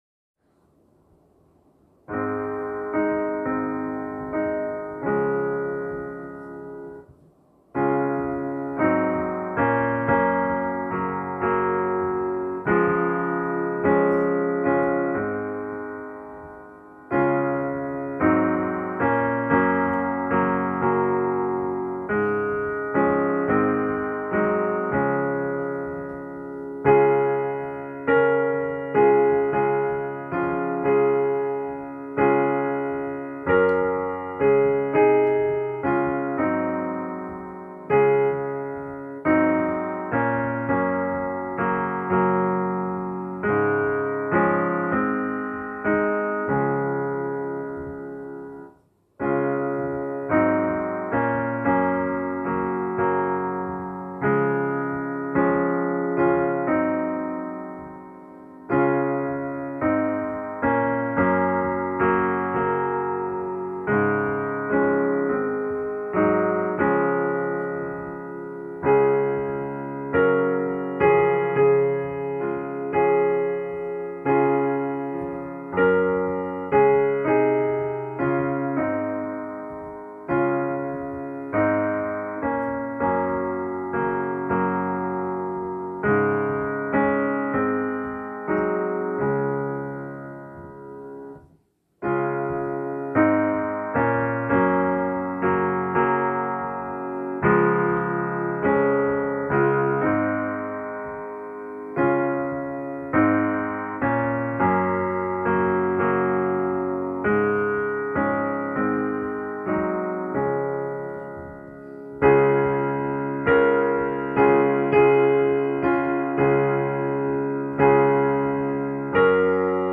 Pianosoolo